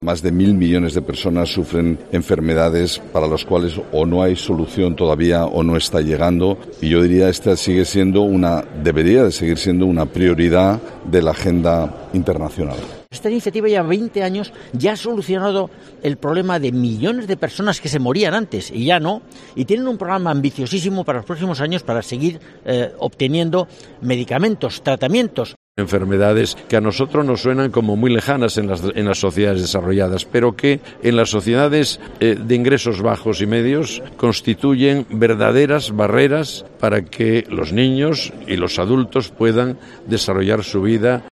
miembros del jurado, explican las razones del Premio